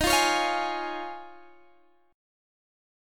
Eb7sus2sus4 chord